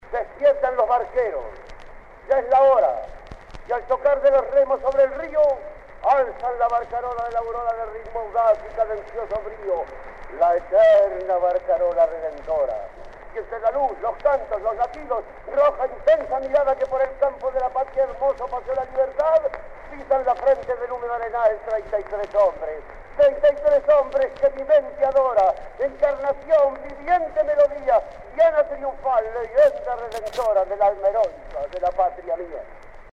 Recitados de Juan Zorrilla de San Martín declamando la Leyenda Patria.
Fueron tomados de un disco de cera que se convirtió a disco de pasata (anterior al vinilo) con gran trabajo de limpieza de ruidos y que en 1975 se publicaron en una edición especial en el Sesquicentenario de los Hechos Históricos de 1825.